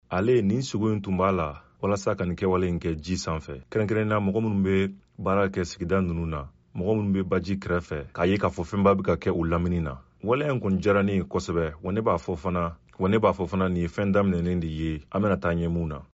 Donkelaw ni dɔnkilidala kamalennin 12 bɛ ka kurun dɔ planw gosi ka jimbe mankan na Nizɛri baji kan Bamakɔ .
dɔnkilidala kamalennin 12 bɛ ka kurun dɔ planw gosi ka tabale mankan na Nizɛri baji kan Bamakɔ . U bɛ ka foli laben kɛ Mali kɔnɔ, seko ni dɔnko kama: Pirogue du Zémé. repetition kɛ Mali kɔnɔ, seko ni dɔnko yɔrɔ fɔlɔ min bɛ panpan, o dabɔli kama: Pirogue du Zémé.